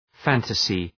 {‘fæntəsı}